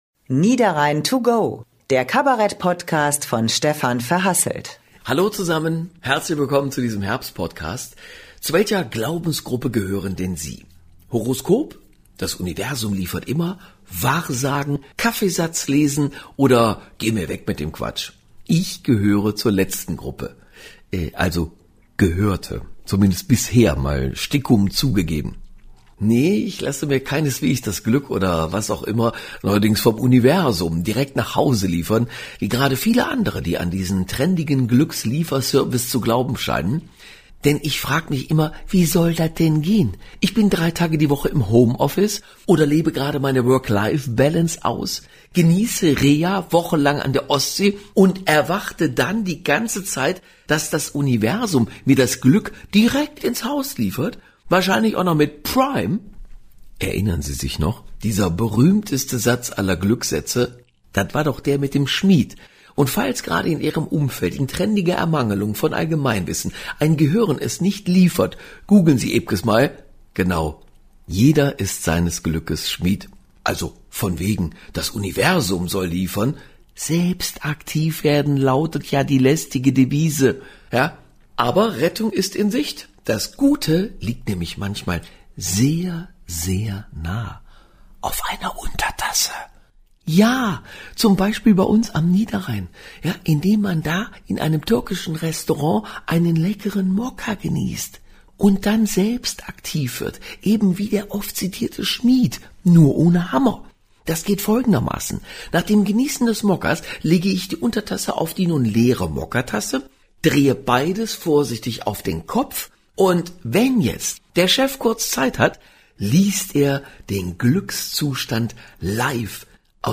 Kabarett-Podcast